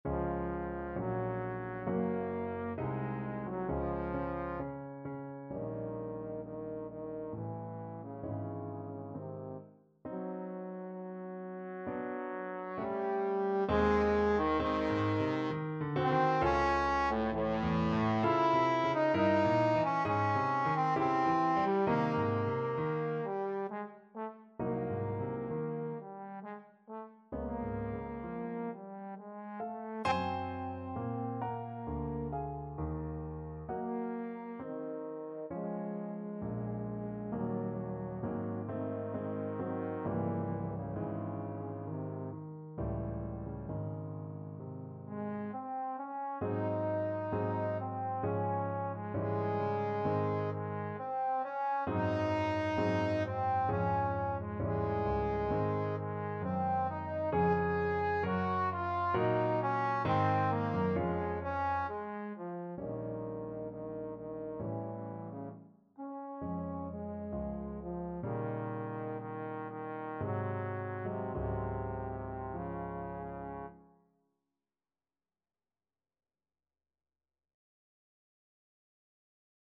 Andante Sostenuto, Main Theme Trombone version
Trombone
=66 Andante sostenuto
Db major (Sounding Pitch) (View more Db major Music for Trombone )
3/4 (View more 3/4 Music)
A3-Ab5
Classical (View more Classical Trombone Music)
brahms_andante_sostenuto_TBNE.mp3